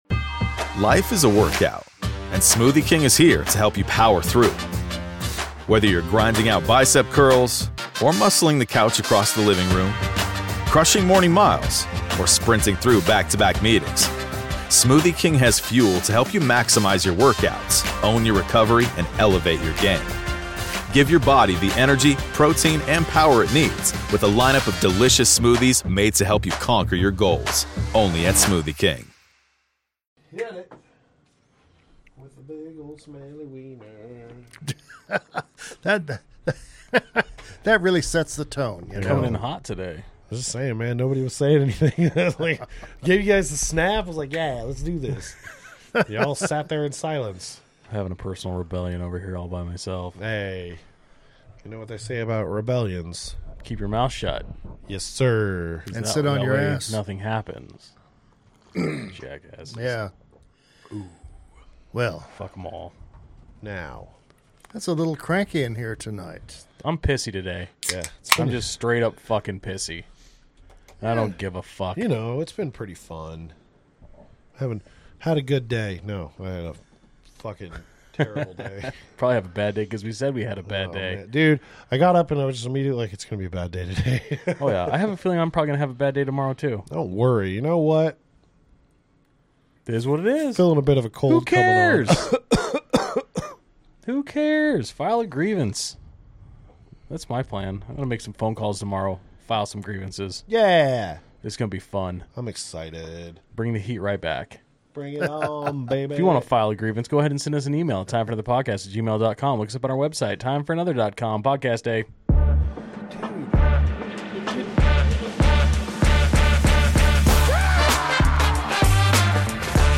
Play Rate Listened List Bookmark Get this podcast via API From The Podcast 6 Three guys talking about everything from current events to politics to food to wild stories we find on the internet and anything in between. The kind of conversations you have sitting at a bar or hanging with your buddies in the garage having a few cold ones (which we encourage greatly).